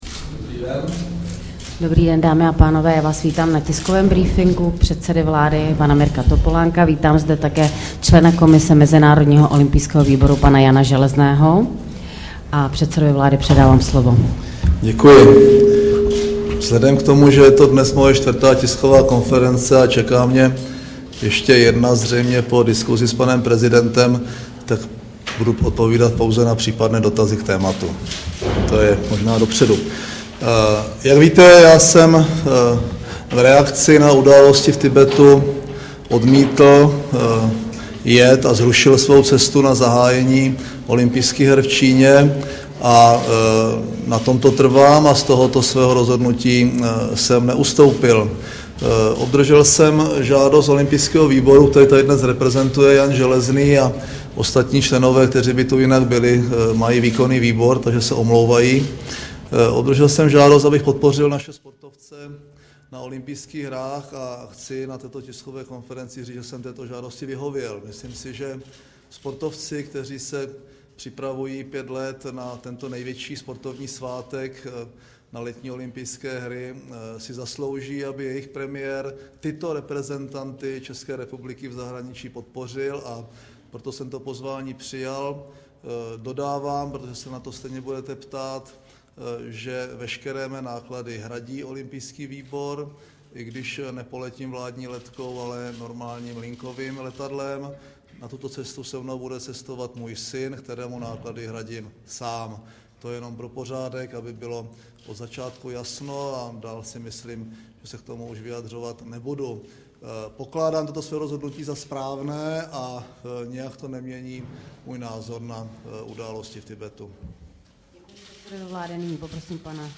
Zvukový záznam tiskové konference